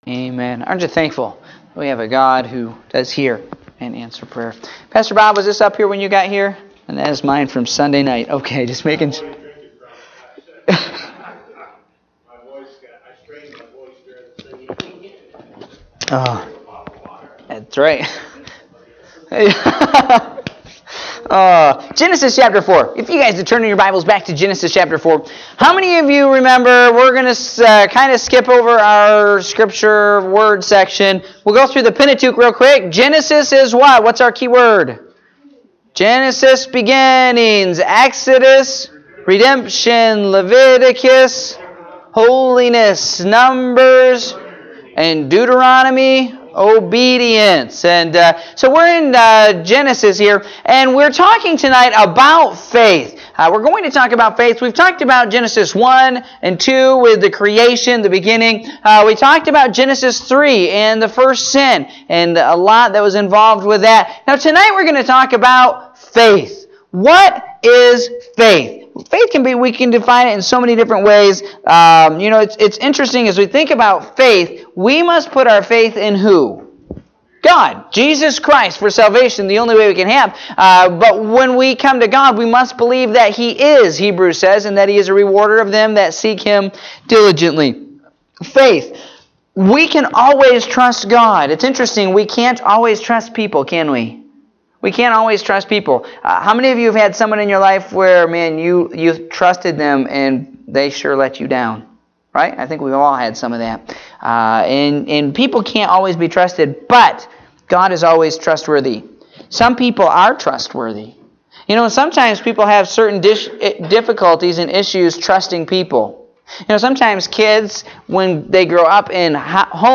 Prayer Meeting (3/07/2018)